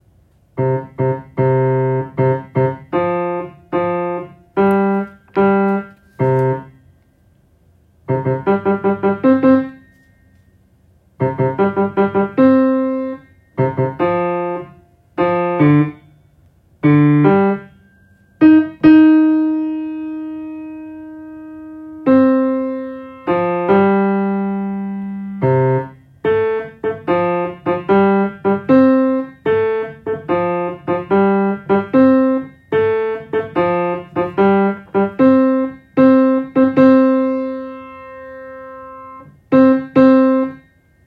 音とり音源
バス